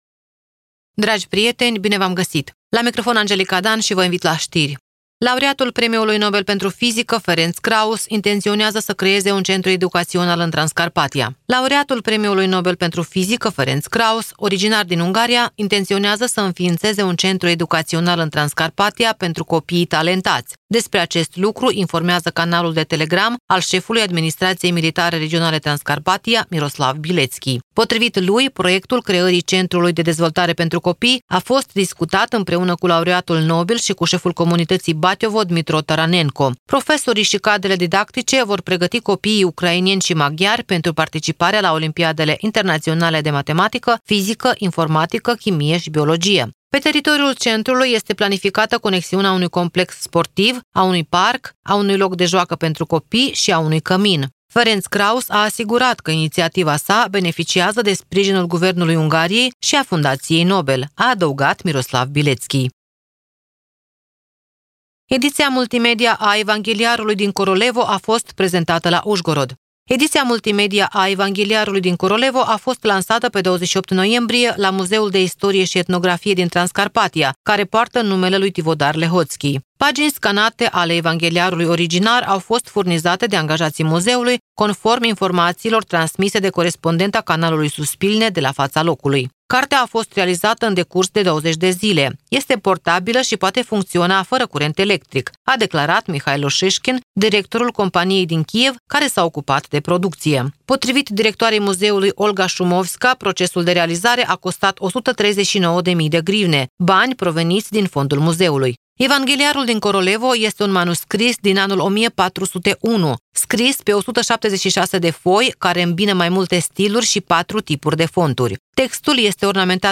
Știri de la Radio Ujgorod.